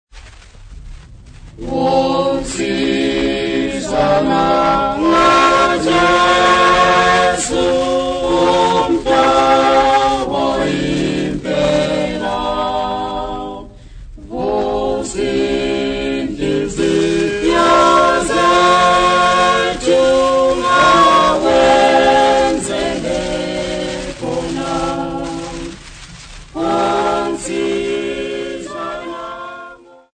Lower Gwelo Choir
Folk Music
Africa Zimbabwe city not specified f-rh
field recordings
sound recording-musical
Indigenous music